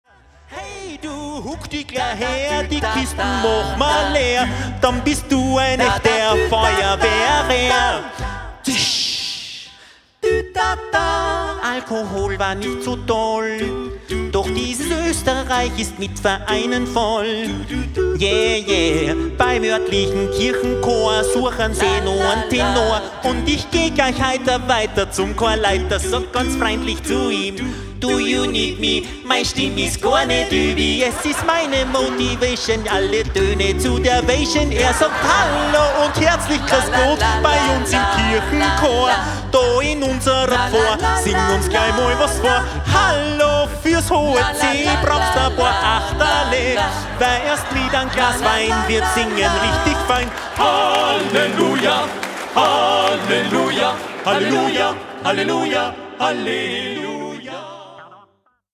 die LIVE-CD zum gleichnamigen Programm